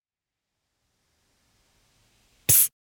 ScreecherPsst.ogg